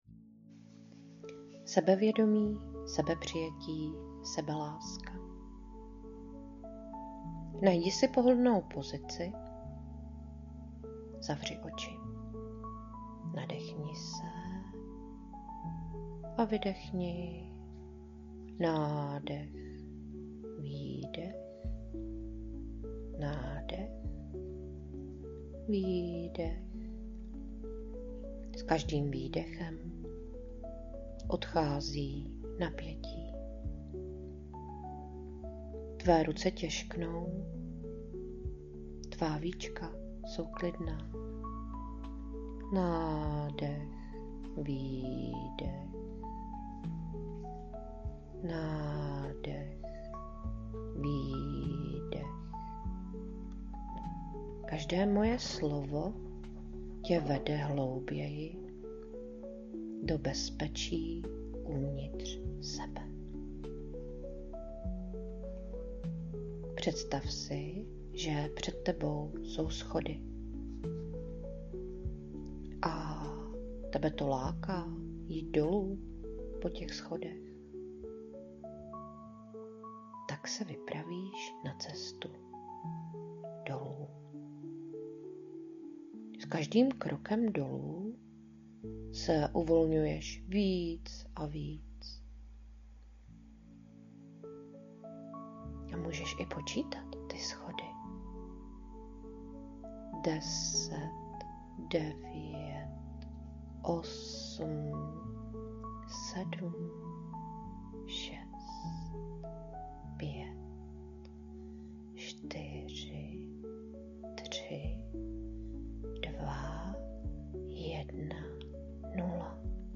Zvuková meditace téma sebevědomí, sebepřijetí, sebeláska